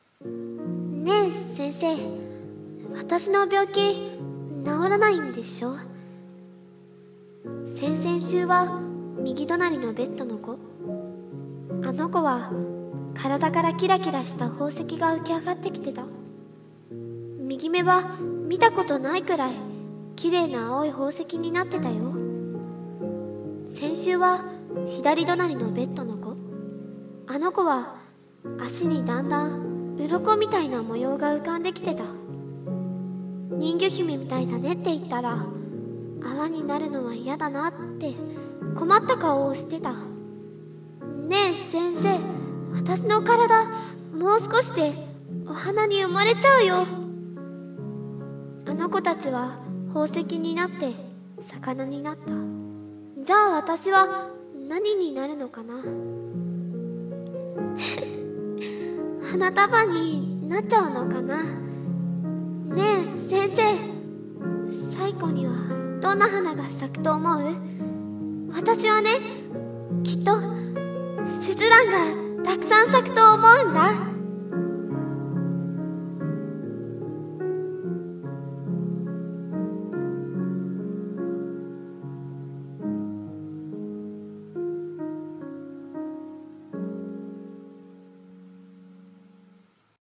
【一人声劇】スズランの花束